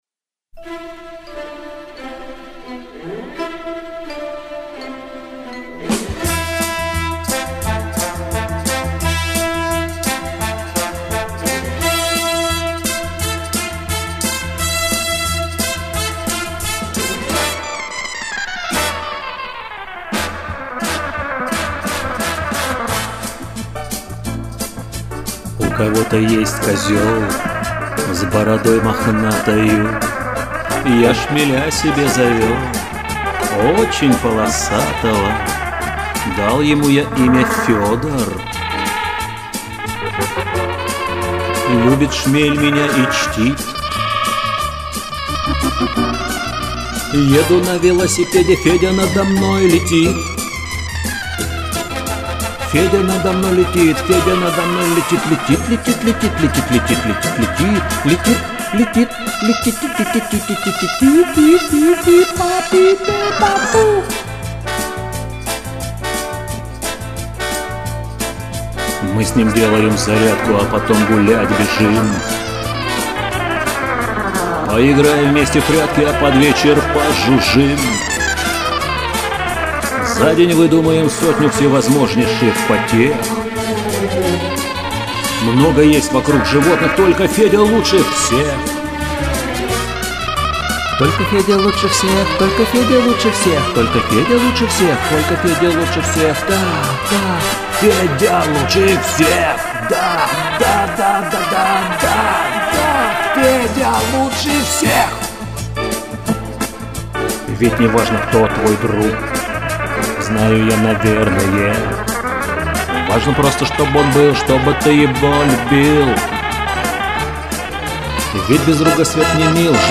Инфернально прозвучал - прям чистый Хичкок.